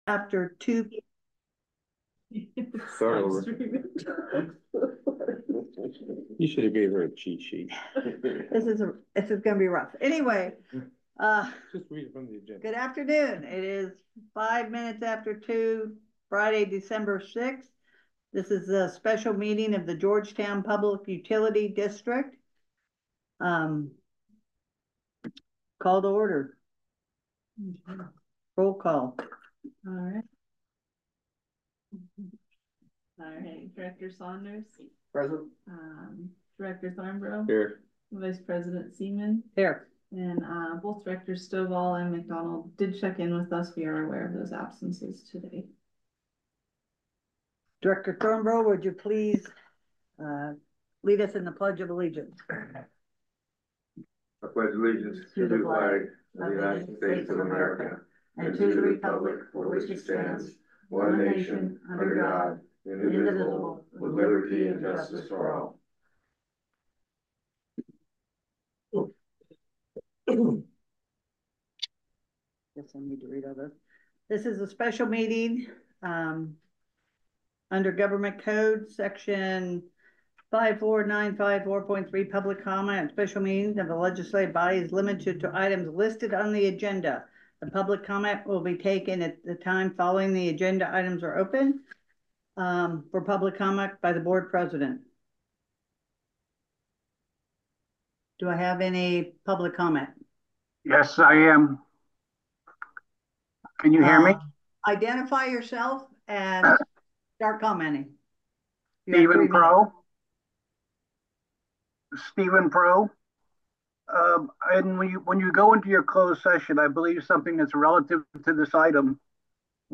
Special Board Emergency Board Meeting